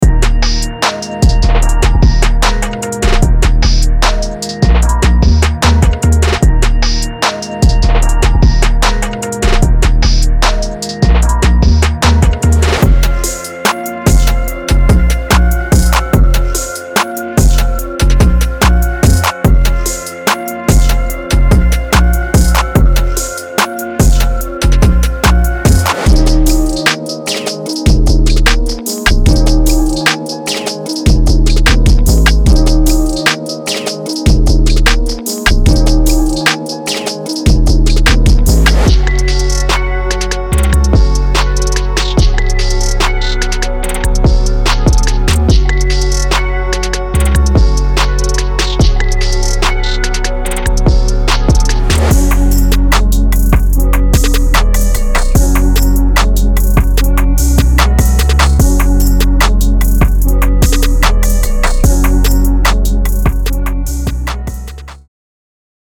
Hip-Hop / R&B Trap
is a dark and atmospheric pack